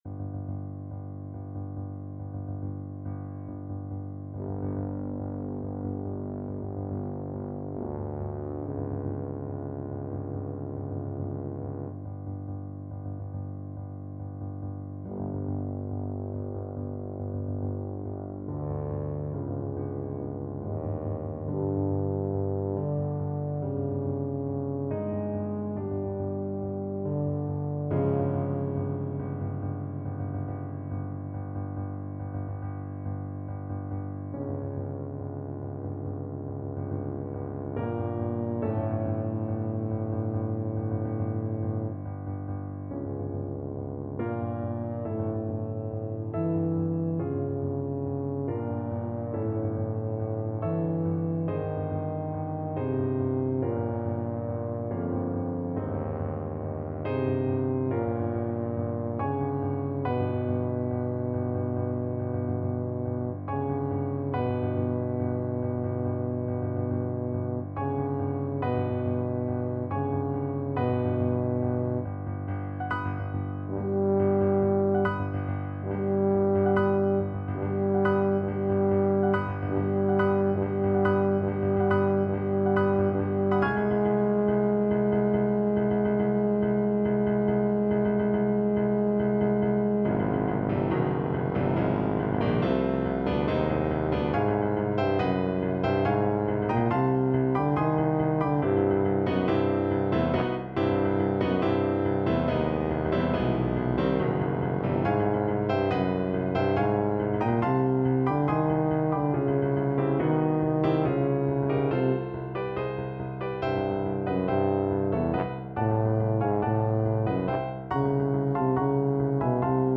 Tuba
5/4 (View more 5/4 Music)
Allegro = 140 (View more music marked Allegro)
A minor (Sounding Pitch) (View more A minor Music for Tuba )
Classical (View more Classical Tuba Music)